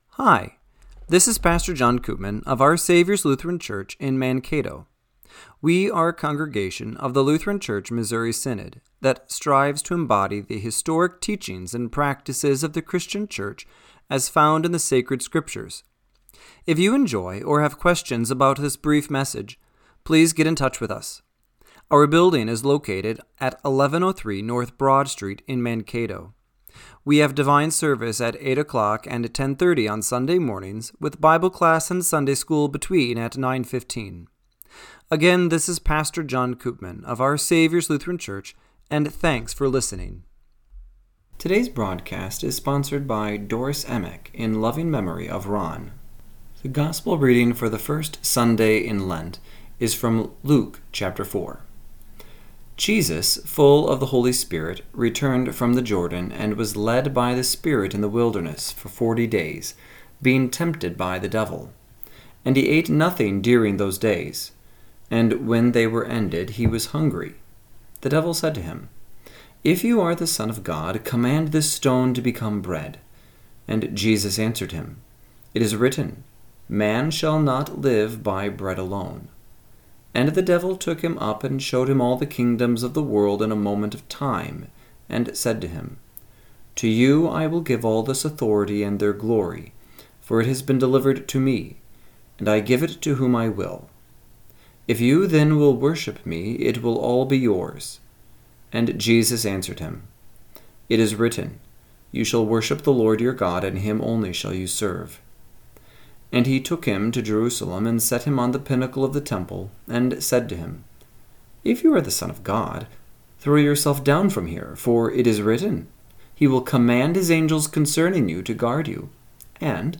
Radio-Matins-3-9-25.mp3